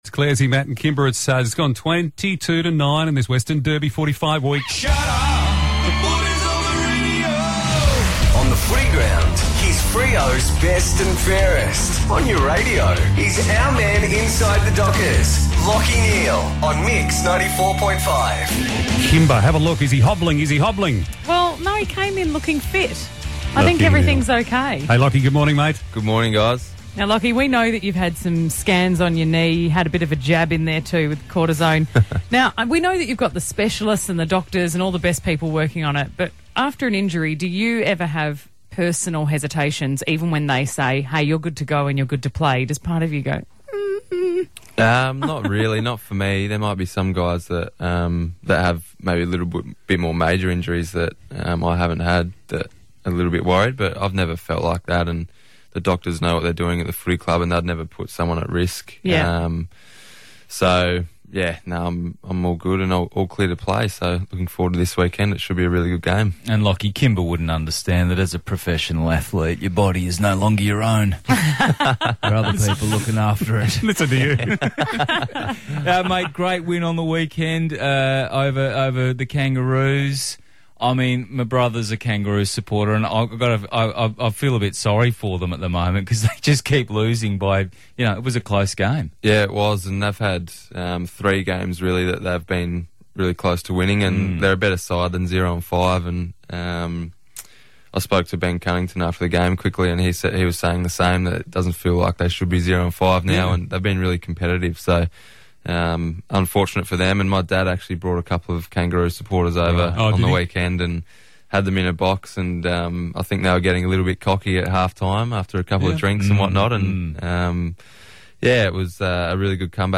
Lachie Neale chats to the team at 94.5 ahead of Freo's clash against the Eagles.